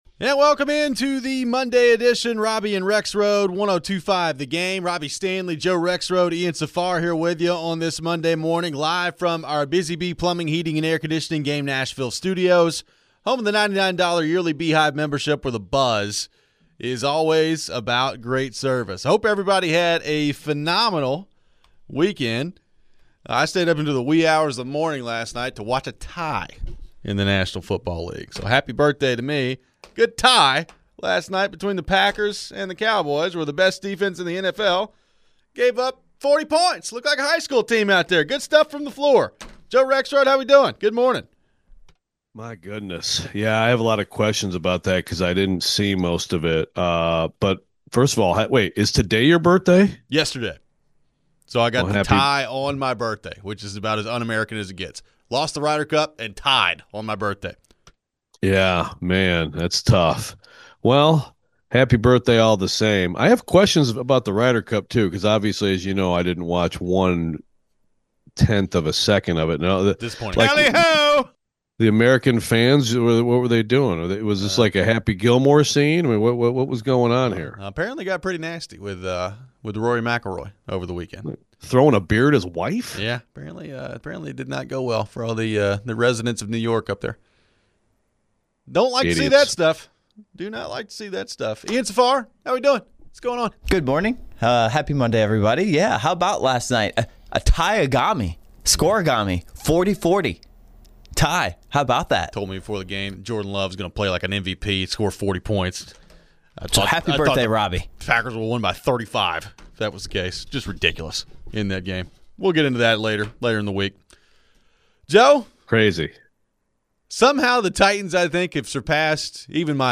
Will the Titans fire Callahan this week? We take your calls.